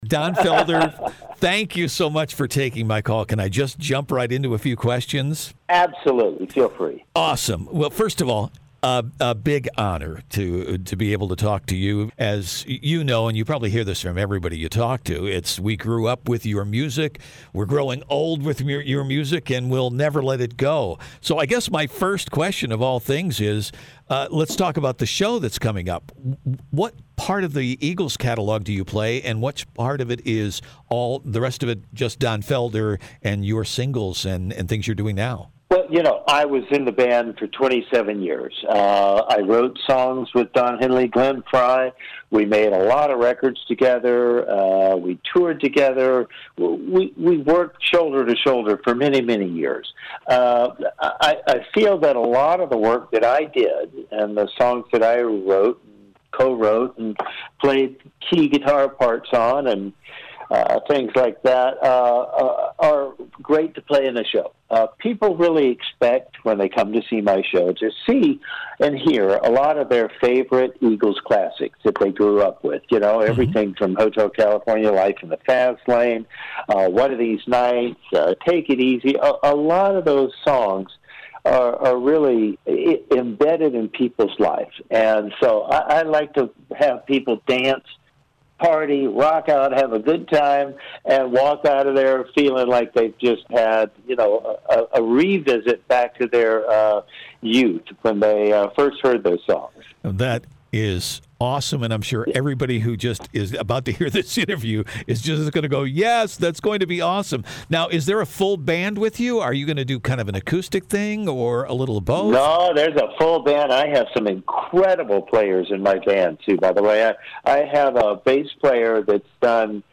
Some nuggets from the interview:
donfelder-full-interview.mp3